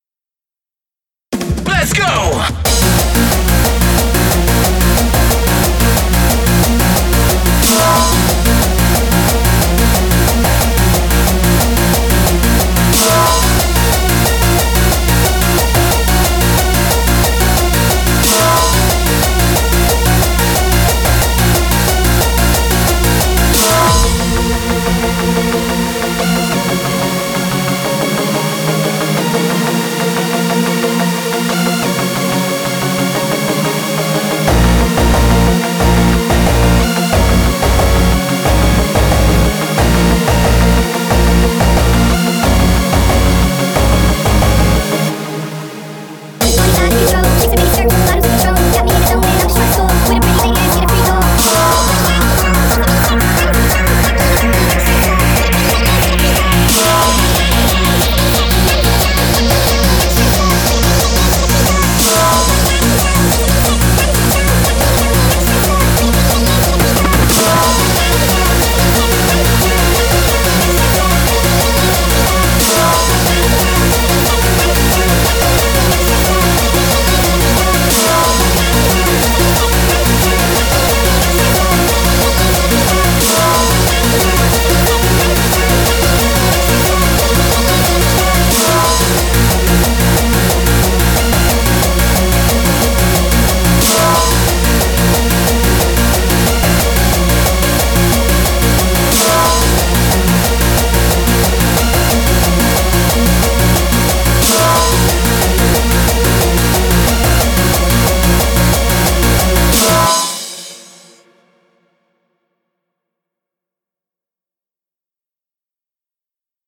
スカルスネアが使いたかっただけの曲.mp3